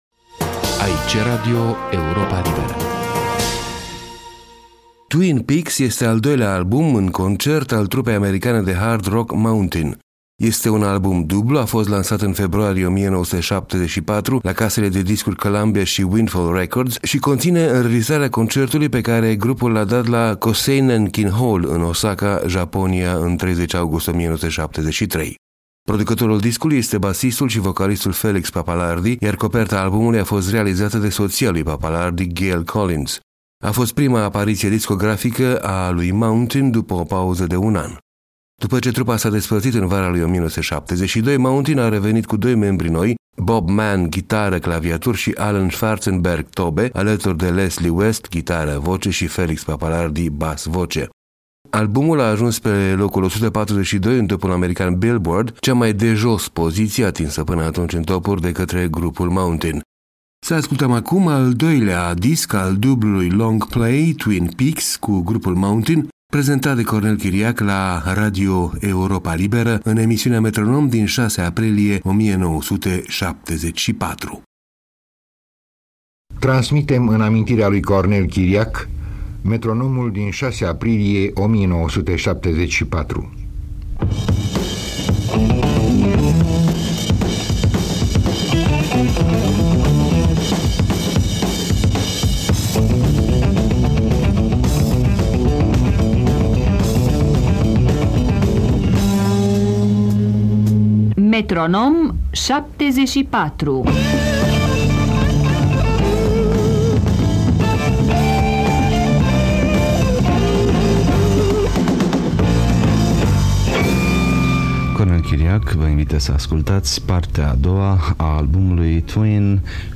album în concert
hard rock